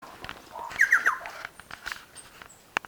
Chucao Tapaculo (Scelorchilus rubecula)
Life Stage: Adult
Location or protected area: Parque Municipal Llao Llao
Condition: Wild
Certainty: Observed, Recorded vocal